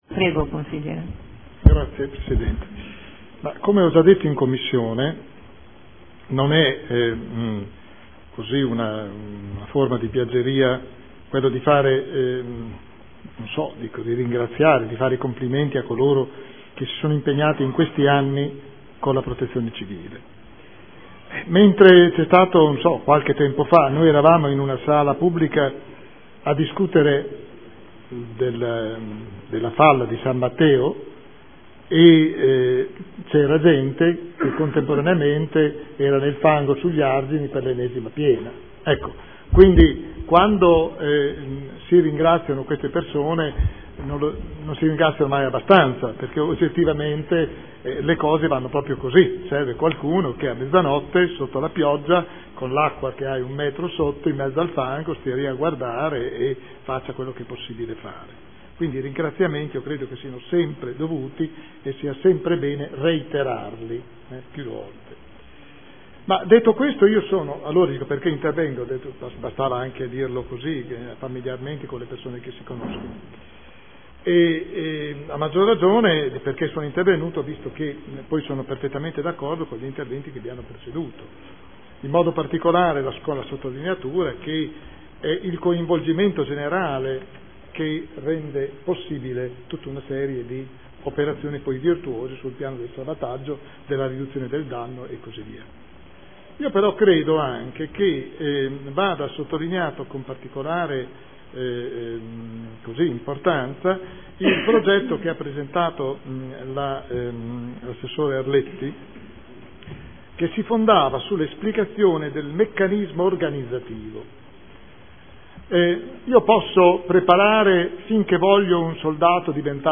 Seduta del 20/03/2014 Aggiornamento del Piano comunale di protezione civile